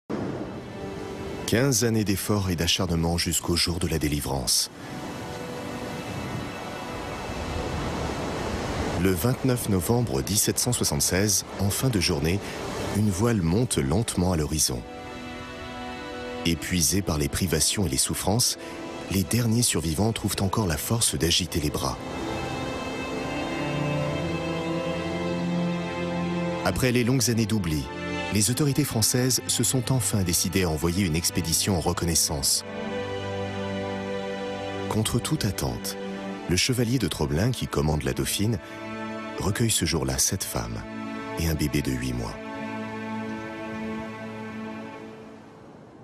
Prestation voix-off pour "L'île de Tromelin" : une narration douce, dramatique et sensible
Voix narratrice et sensible.
J’ai opté pour une tonalité médium grave, qui a permis de donner une dimension à la fois sensible et dramatique à la narration.
Un ton doux et posé a été utilisé pour raconter l’histoire de cette île, permettant ainsi de transporter les téléspectateurs dans un univers à la fois mystérieux et fascinant.